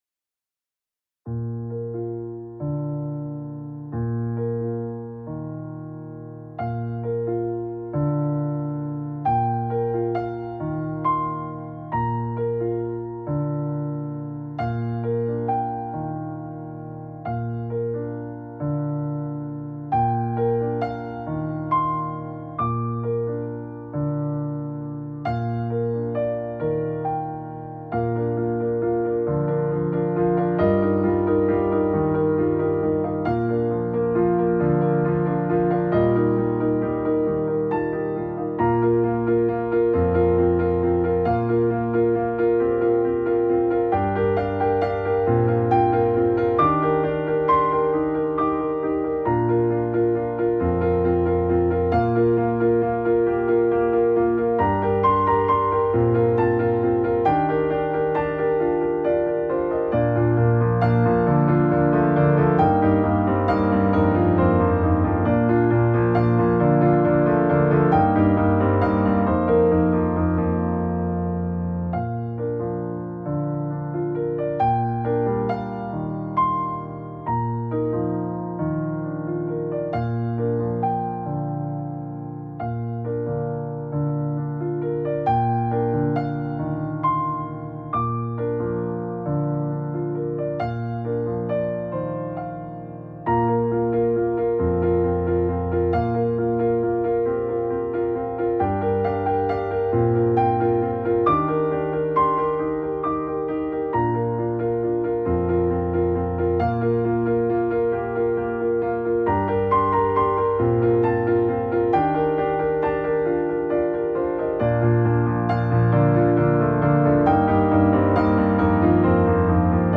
Genre: contemporarypiano, filmscore, newage.